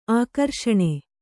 ♪ ākarṣaṇe